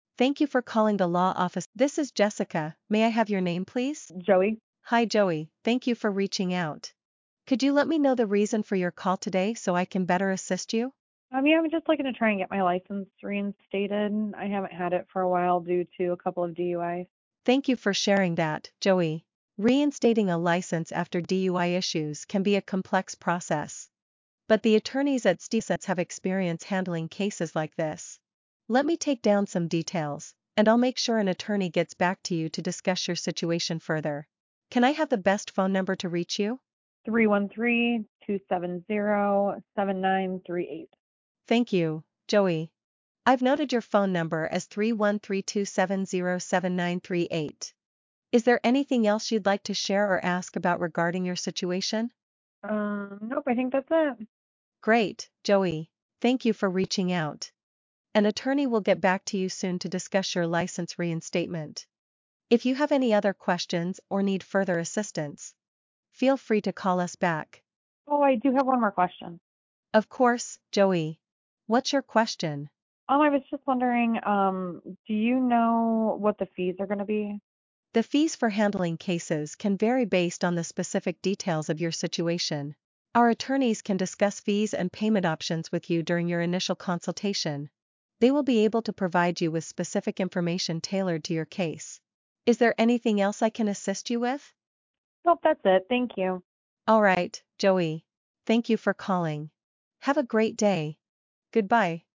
Answer United’s AI Answering Service is designed to give businesses a human-sounding virtual receptionist available 24/7.
• Real-time speech in/out for natural dialogue
• True conversation flow with natural turn-taking
Here is a sample AI Attorney Call: